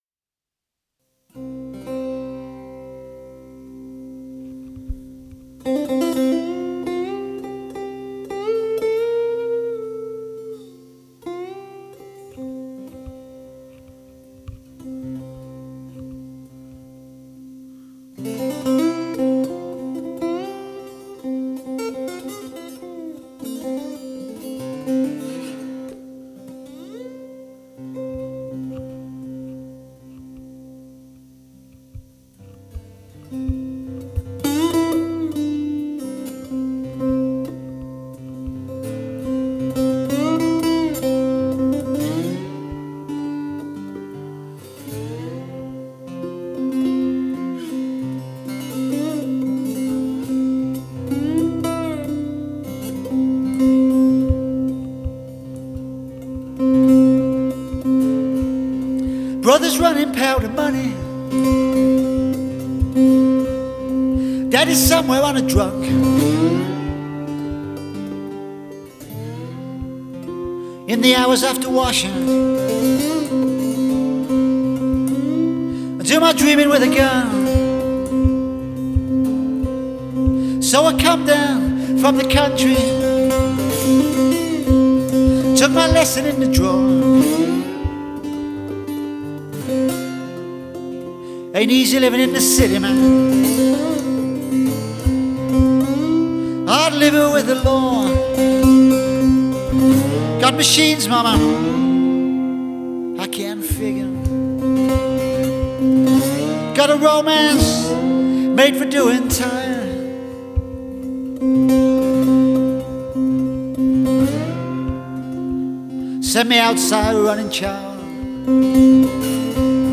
Blues-Folk singer/songwriter/guitarist